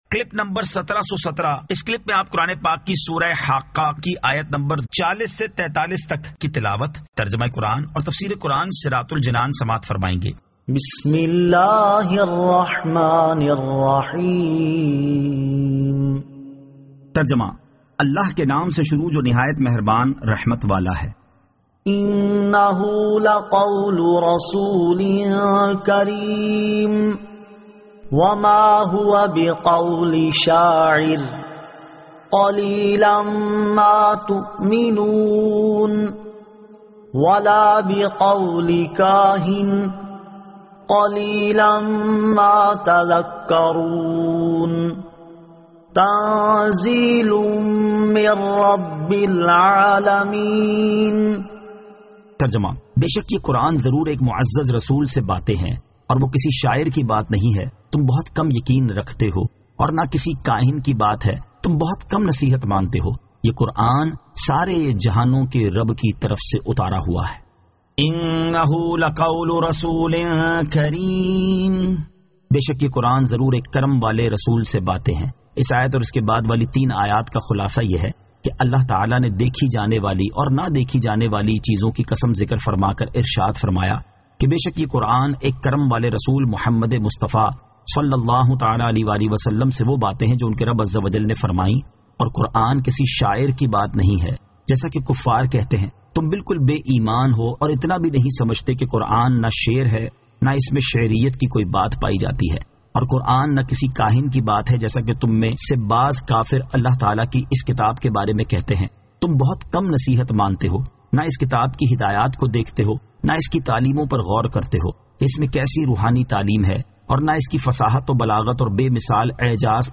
Surah Al-Haqqah 40 To 43 Tilawat , Tarjama , Tafseer